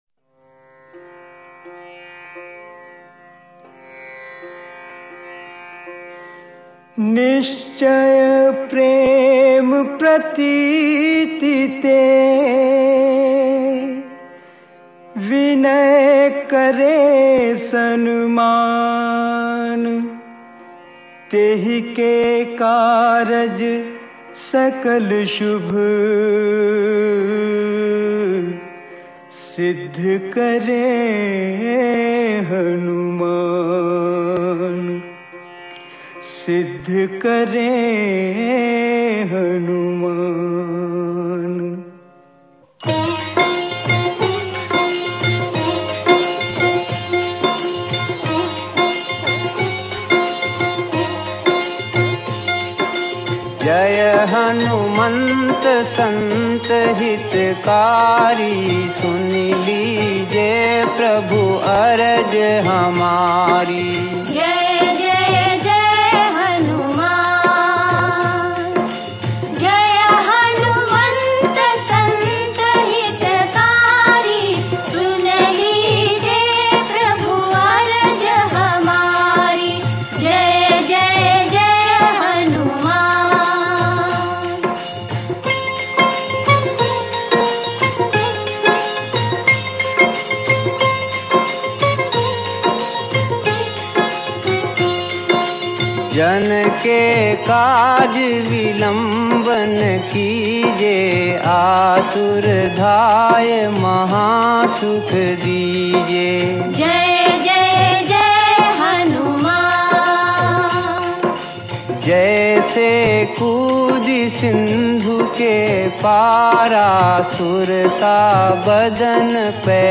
Devotional Songs
Shree Hanuman Single Bhajan